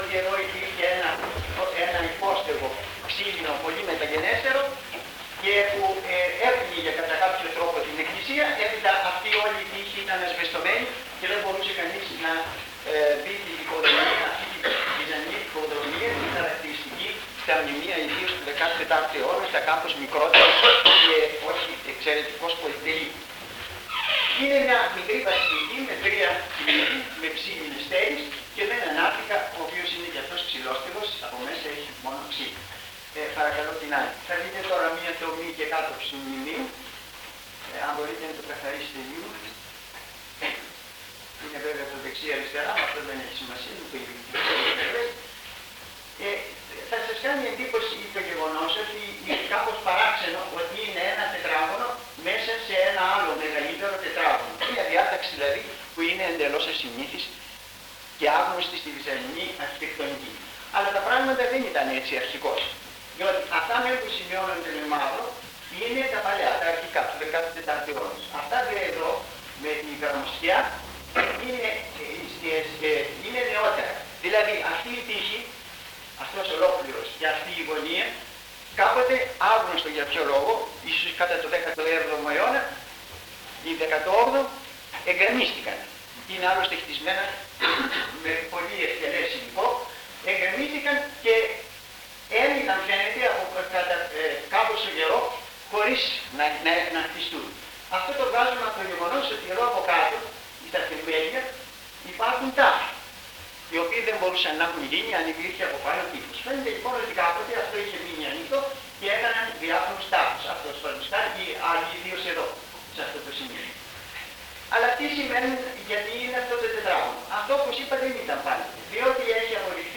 Εξειδίκευση τύπου : Εκδήλωση
Εμφανίζεται στις Ομάδες Τεκμηρίων:Εκδηλώσεις λόγου